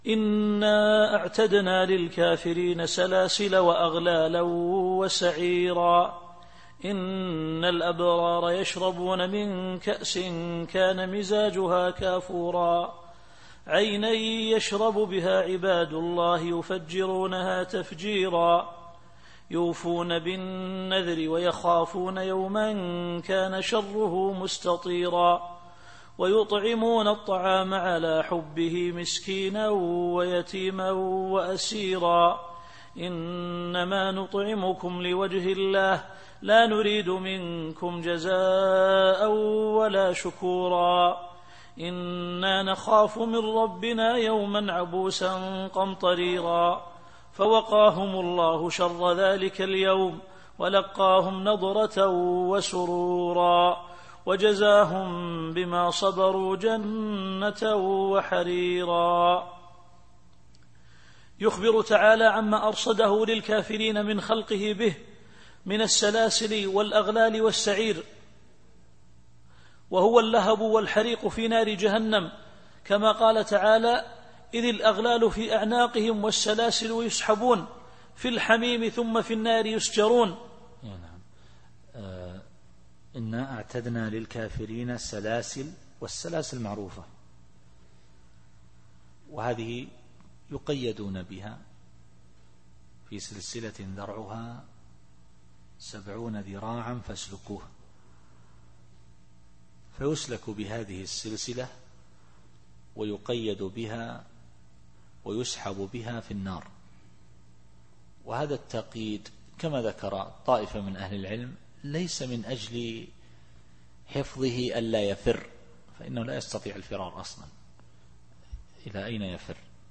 التفسير الصوتي [الإنسان / 4]